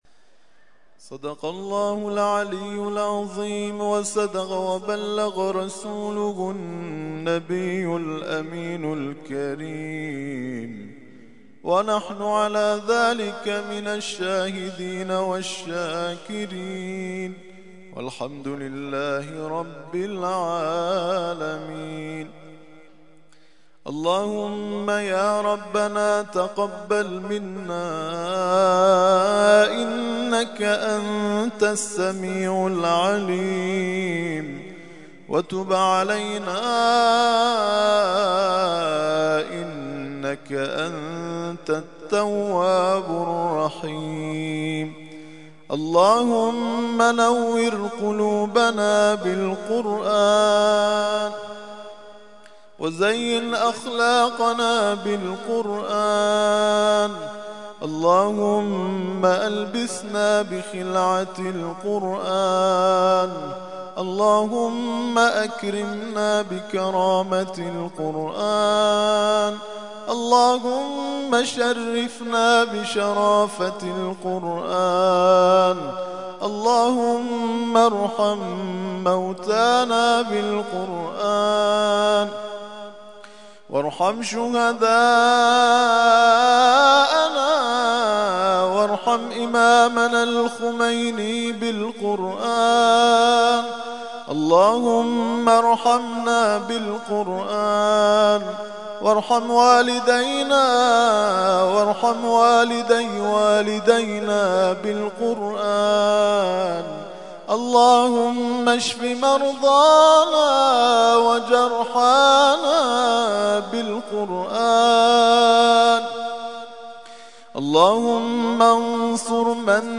ترتیل خوانی جزء ۲ قرآن کریم در سال ۱۳۹۱
دعای ختم قرآن